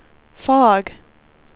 speech / tts / prompts / voices
weather_50.wav